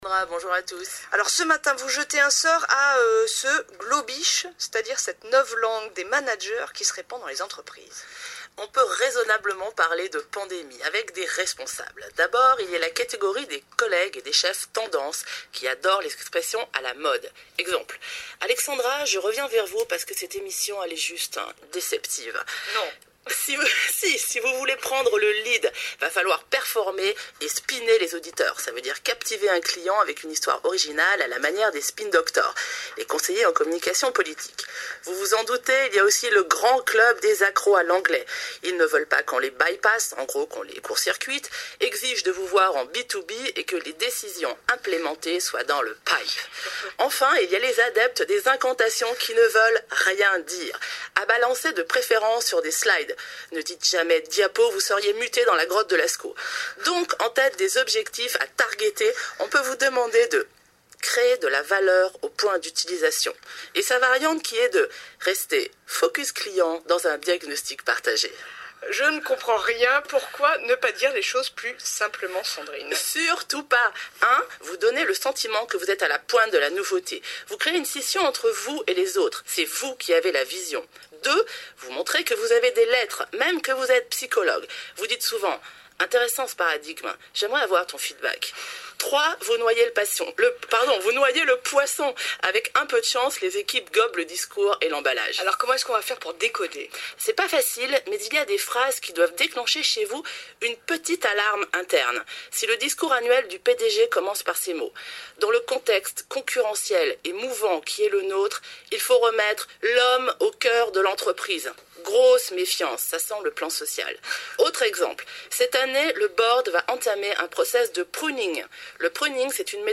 Et aussi au travail, comme raconté ici avec humour: